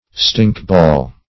Stinkball \Stink"ball`\, n.